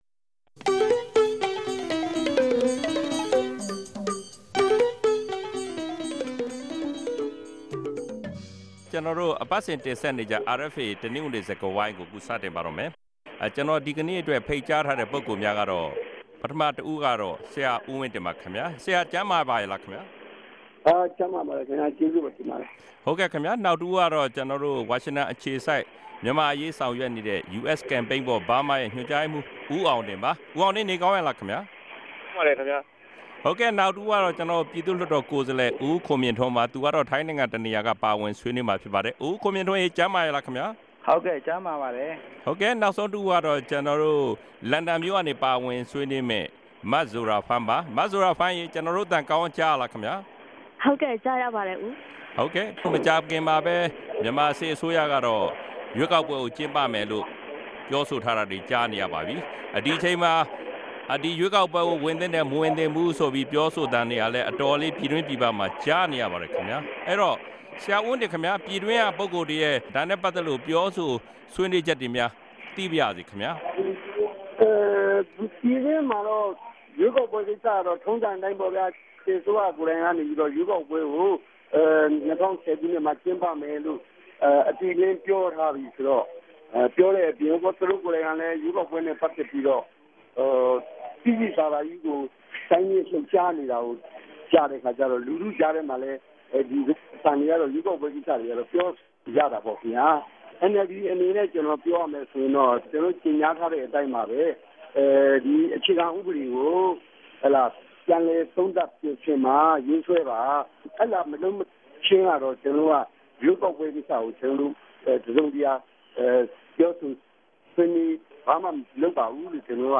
တနဂဿေိံစြကားဝိုင်း။